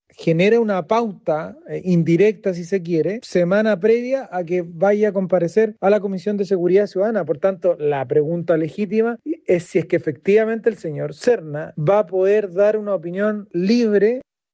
En esa línea se manifestó el diputado y jefe de bancada del PDG, Juan Marcelo Valenzuela, quien aseguró que “genera una pauta indirecta”.